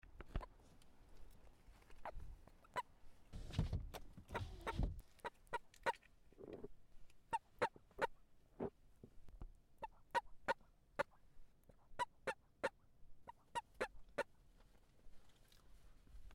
盤古蟾蜍 Bufo bankorensis
新北市 三芝區 三板橋
錄音環境 水溝
1隻鳴叫